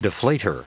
Prononciation du mot deflater en anglais (fichier audio)
Prononciation du mot : deflater